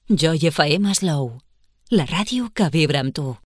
Indicatiu amb la freqüència d'FM.